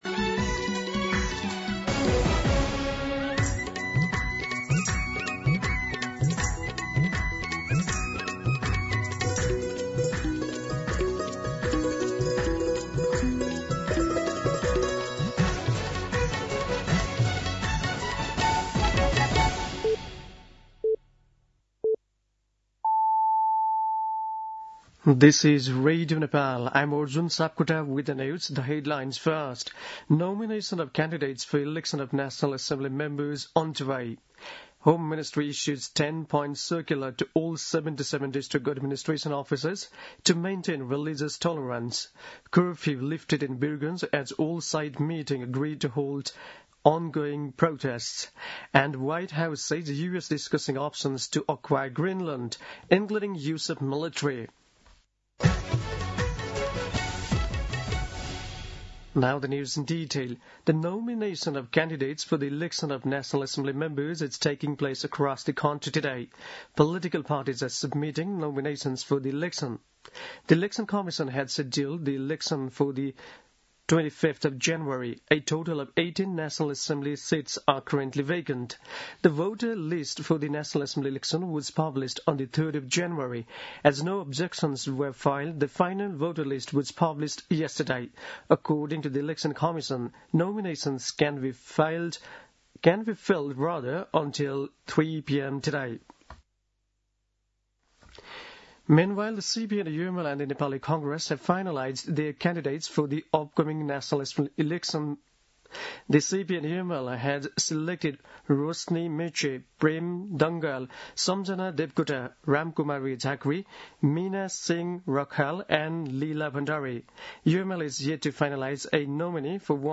दिउँसो २ बजेको अङ्ग्रेजी समाचार : २३ पुष , २०८२
2-pm-English-News.mp3